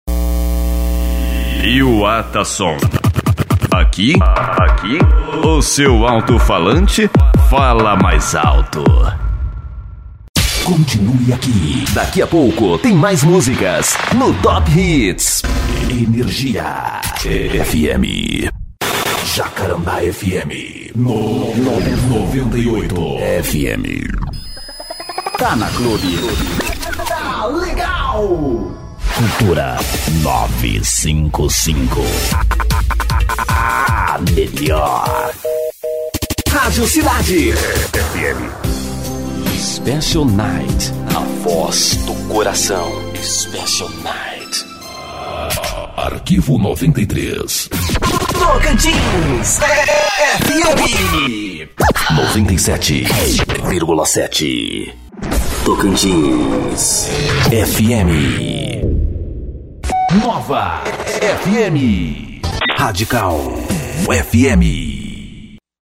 Grave vinhetas, spots comerciais, esperas telefônicas, CD's personalizados e muito mais.
vinhetas.mp3